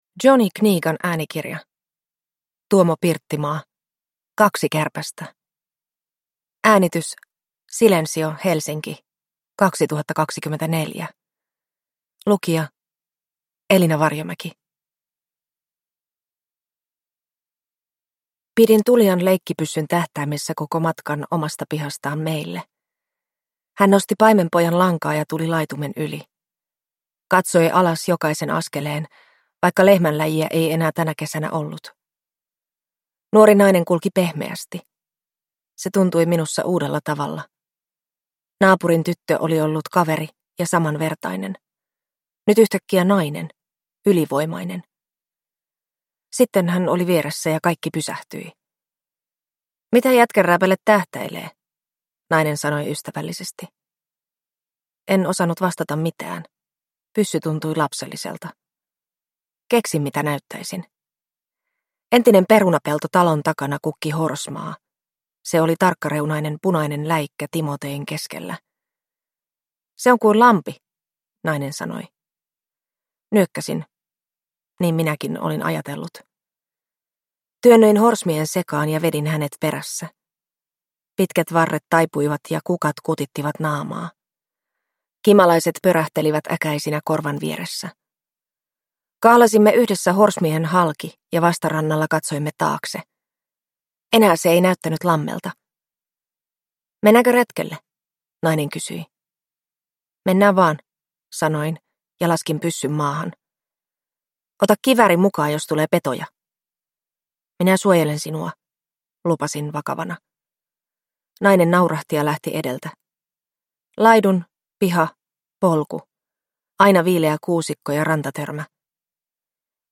Kaksi kärpästä (ljudbok